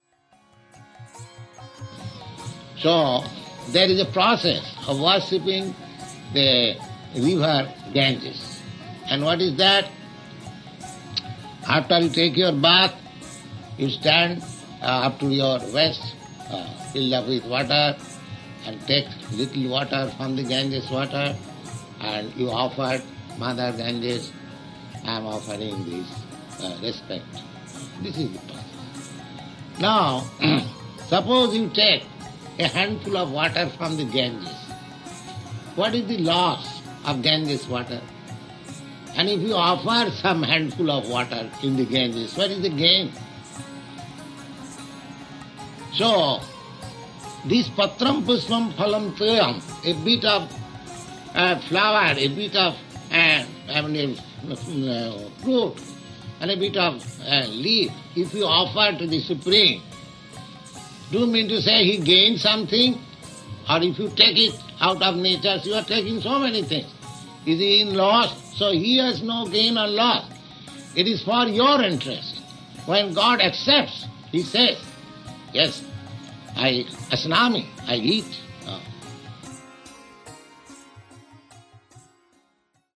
(661216 - Lecture BG 09.26-27 - New York)